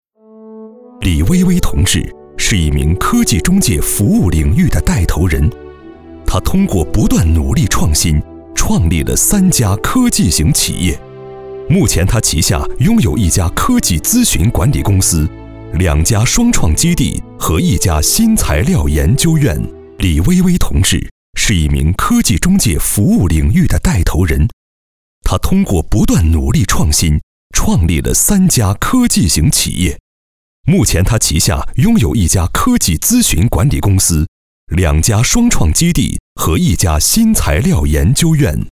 男2号配音师
专题片-男2-“科创中国”党建人物专题.mp3